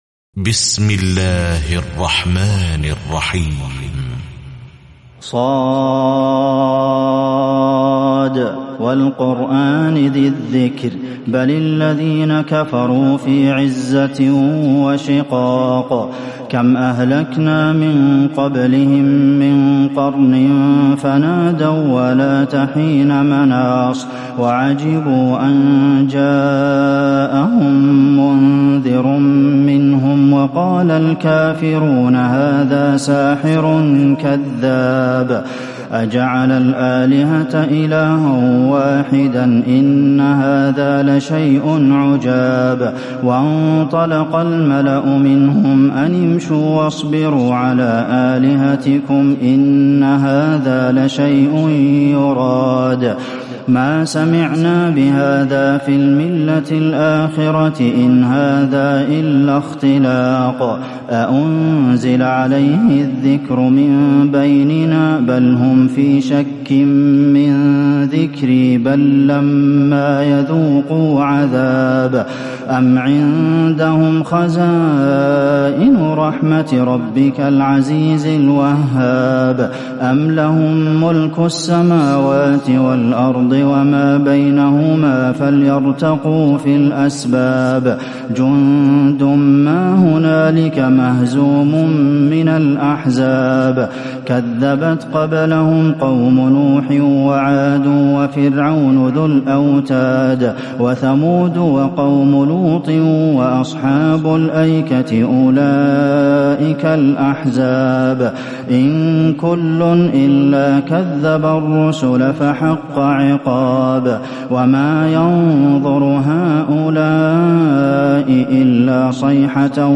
تراويح ليلة 22 رمضان 1438هـ من سور ص و الزمر (1-31) Taraweeh 22 st night Ramadan 1438H from Surah Saad and Az-Zumar > تراويح الحرم النبوي عام 1438 🕌 > التراويح - تلاوات الحرمين